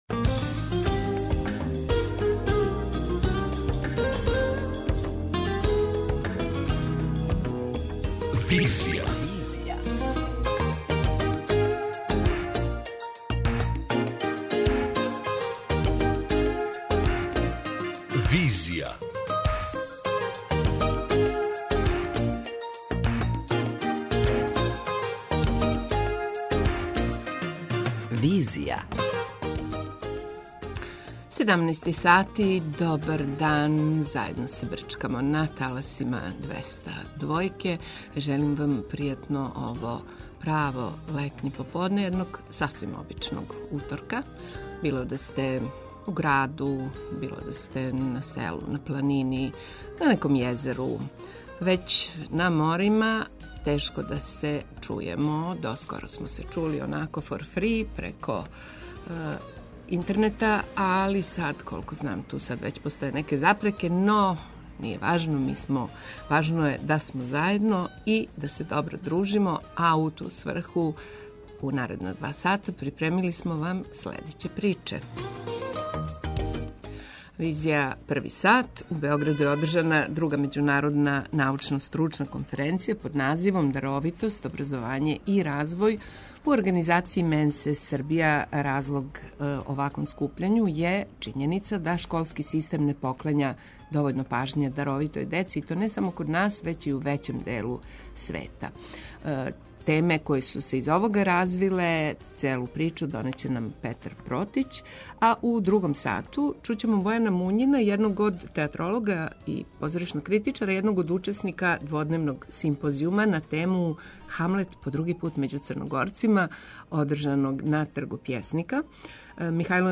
преузми : 55.71 MB Визија Autor: Београд 202 Социо-културолошки магазин, који прати савремене друштвене феномене.